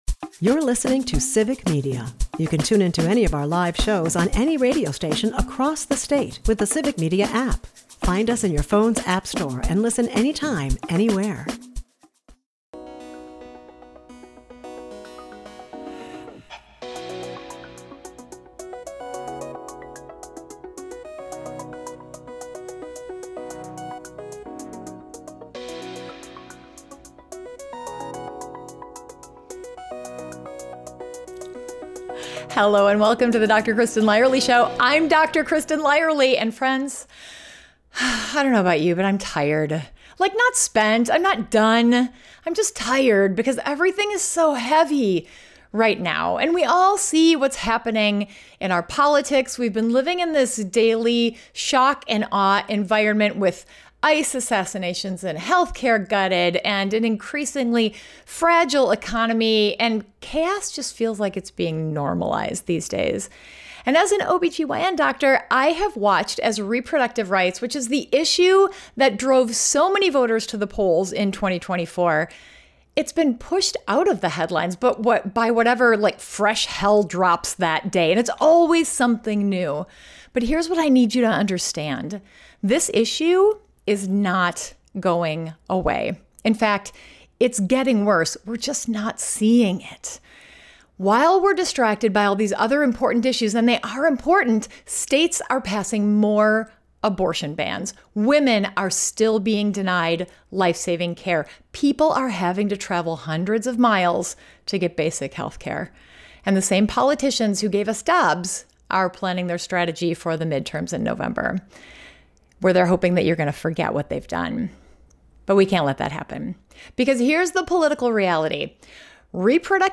In this powerful interview